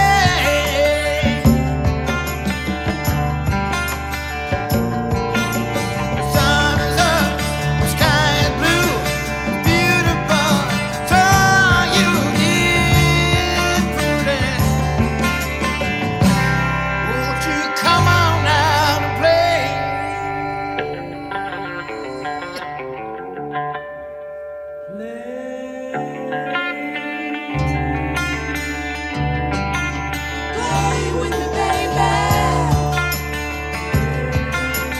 Hard Rock
Жанр: Рок